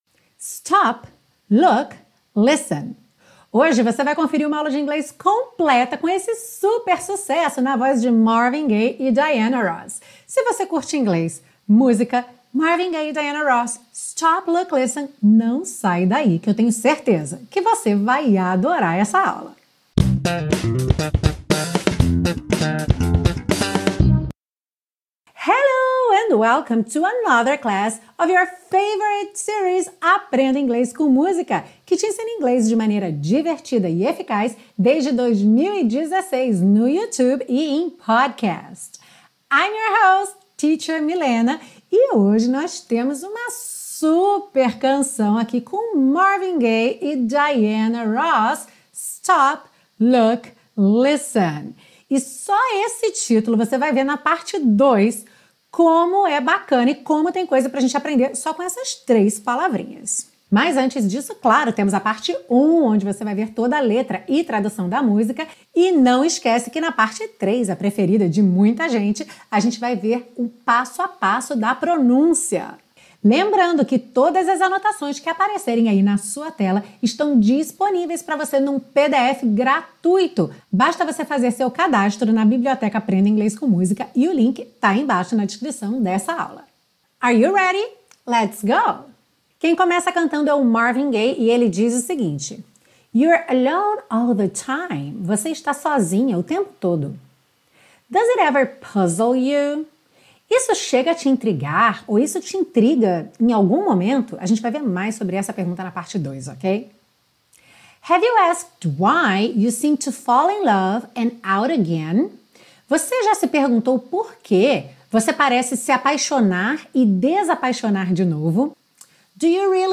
Esta é uma aula de inglês completa, com a compreensão da letra da música, estudo de estruturas do inglês presentes na canção e dicas de pronúncia passo a passo para você aprender a cantar!